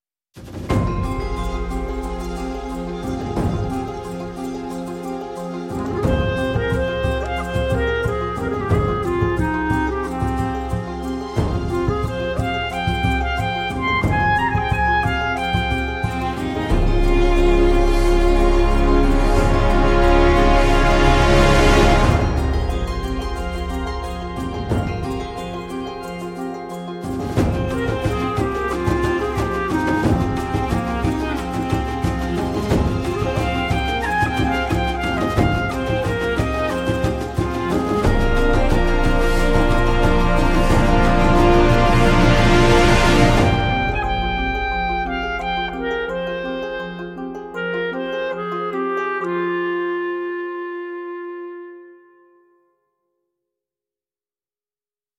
录制于德克萨斯州奥斯汀的 Orb Studois，我们的目标是打造一款灵动而富有情感的单簧管音色。
所有演奏均采用自然渐进的颤音，我们认为这有助于提升音色的温暖度，并拓展乐器的情感表达范围。
“Workhorse”（实用）音色是一款灵动的中强音乐器，非常适合传统欢快的场合。